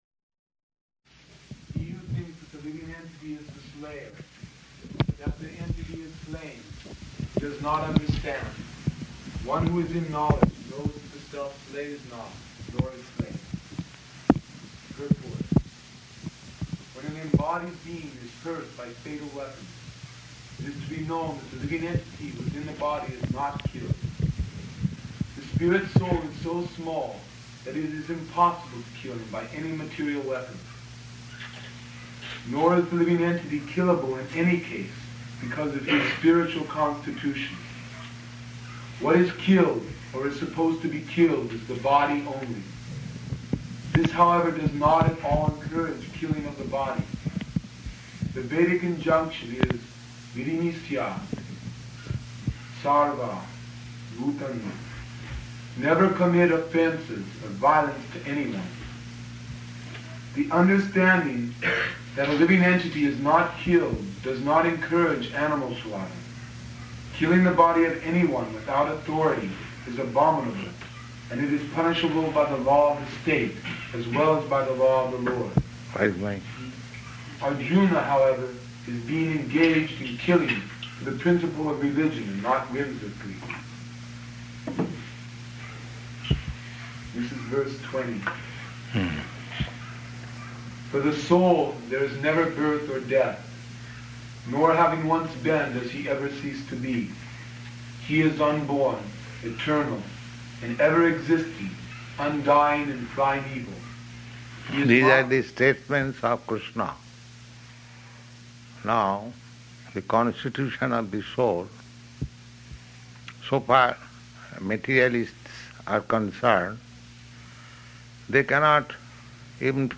Location: Seattle